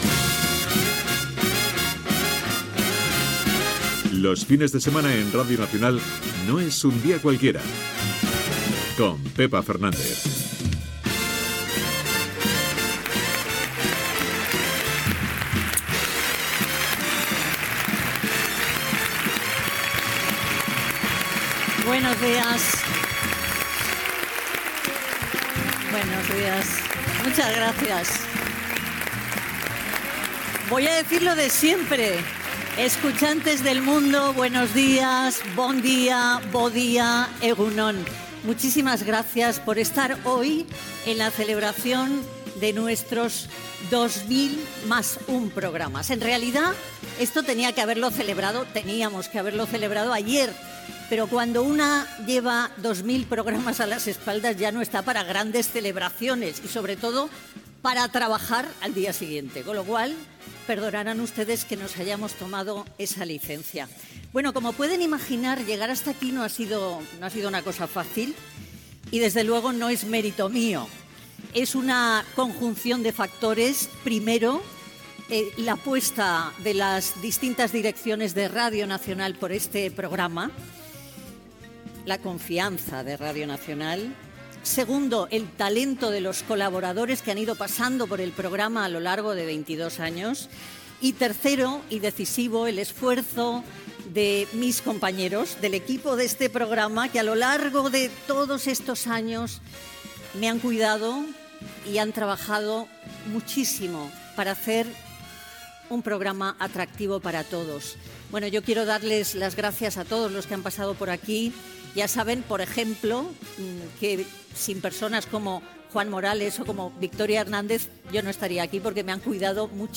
Careta del programa, aplaudiments i presentació del programa especial fet amb motiu del programa 2001 des del Teatro Monumental de Madrid
Entreteniment